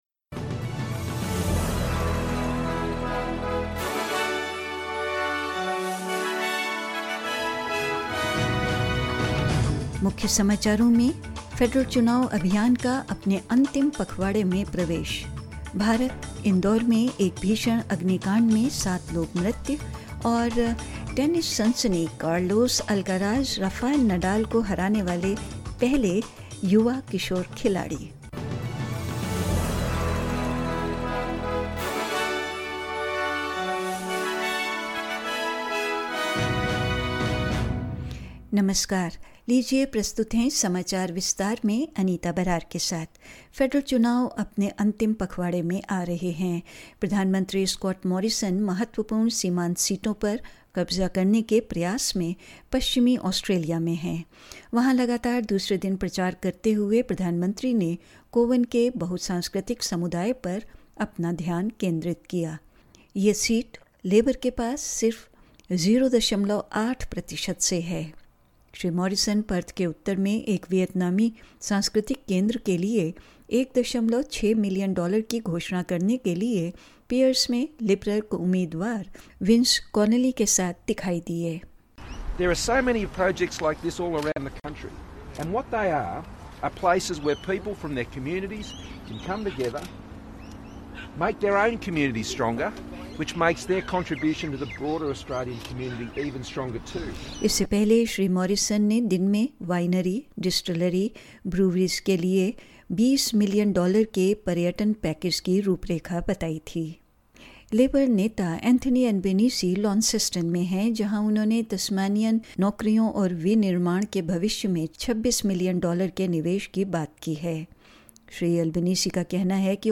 In this latest SBS Hindi bulletin: The federal election campaign enters its final fortnight; In india, fire breaks out in residential building in Indire; Spanish tennis sensation Carlos Alcaraz becomes the first teenager to beat compatriot Rafael Nadal on clay and more news.